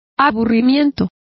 Complete with pronunciation of the translation of tedium.